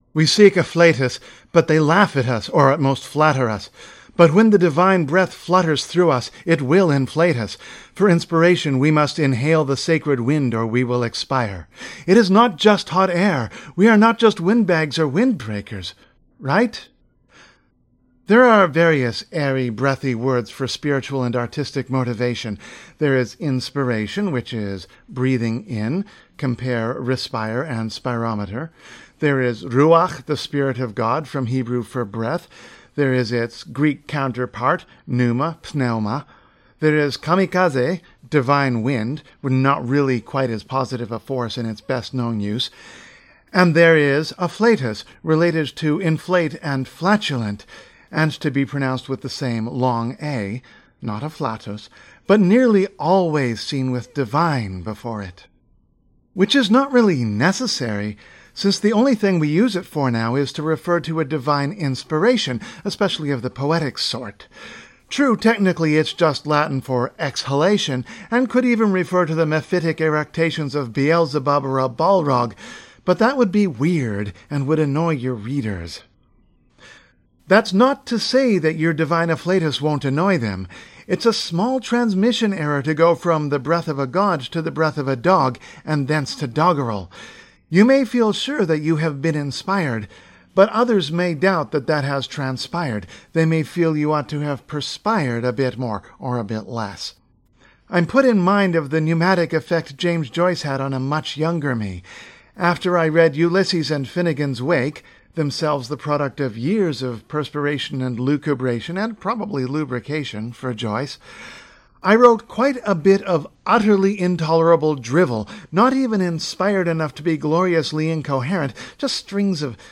Reading: afflatus